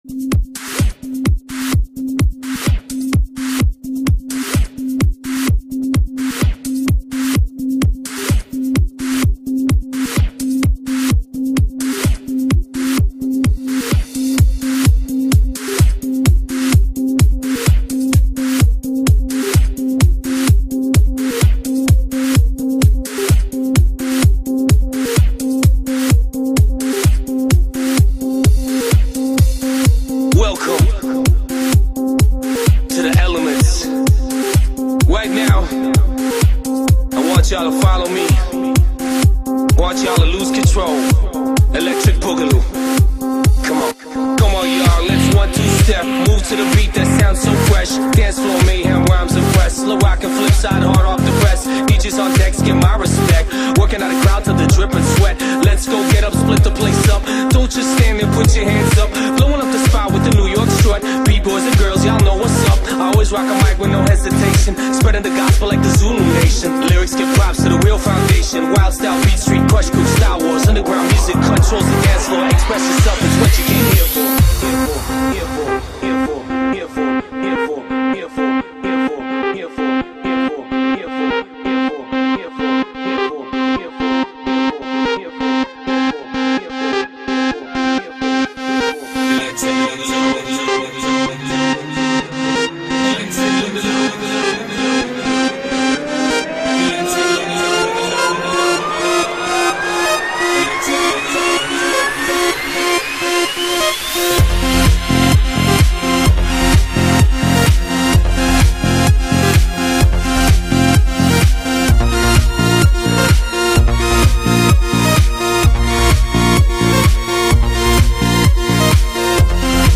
Стиль: Electro / Progressive House